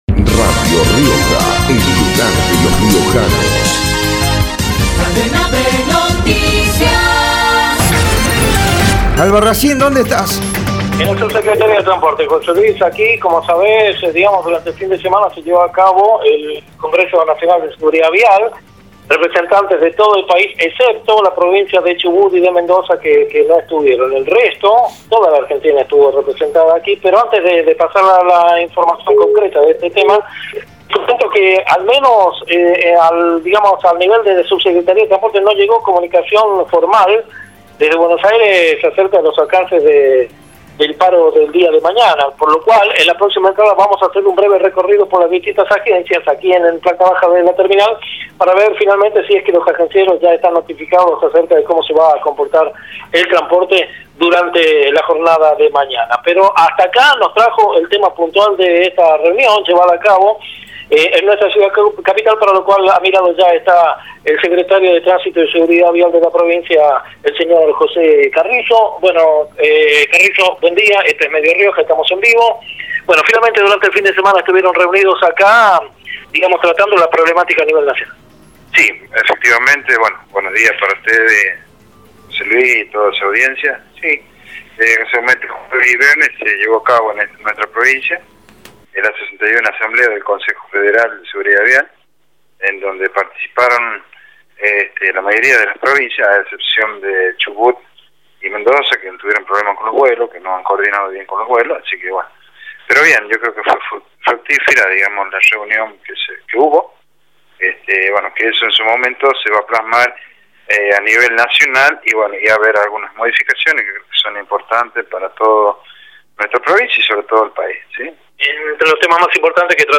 Así lo dijo en Radio Rioja el director general de Tránsito y Seguridad Vial, José Carrizo, al decir: “Yo creería que sí” en relación que la conformación de una empresa municipal de colectivos podría mejorar el servicio urbano de pasajeros en un trabajo coordinado con la Cooperativa Riojano´s.
josc3a9-carrizo-titular-de-transporte-por-radio-rioja.mp3